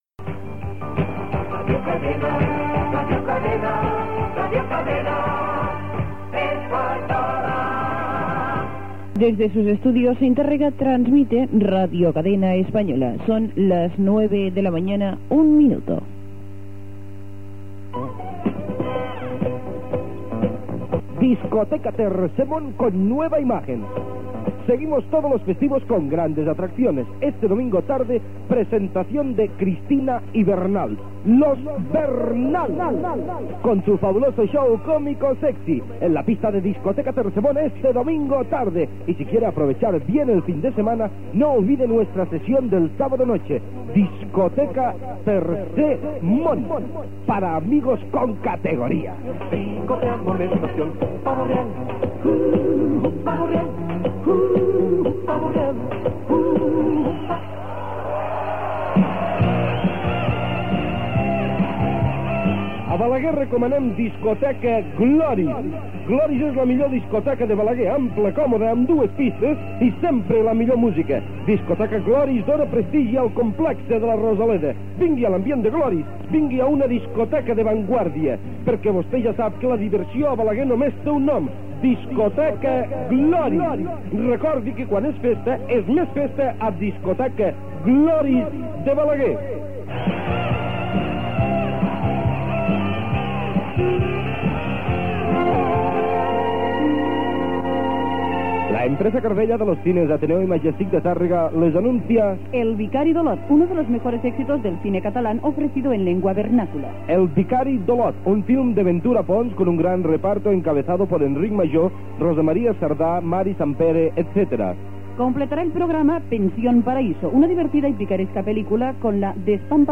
Indicatiu i bloc publicitari
FM